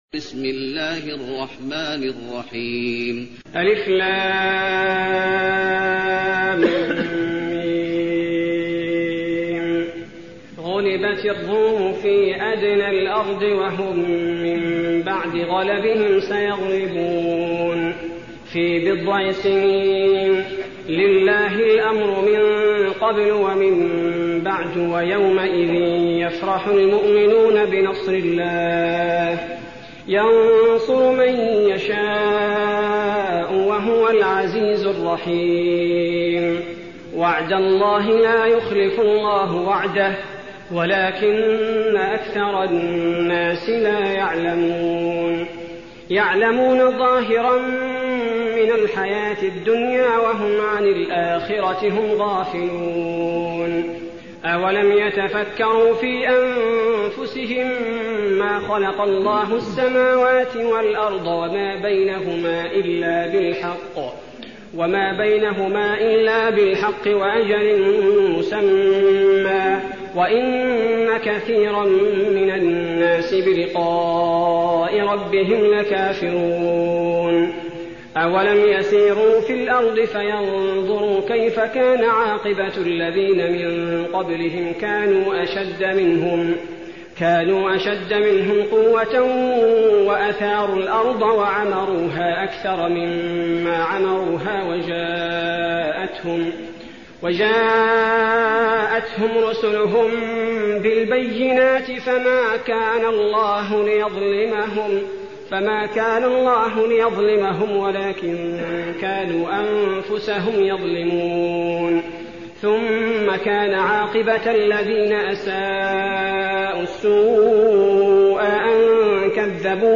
المكان: المسجد النبوي الروم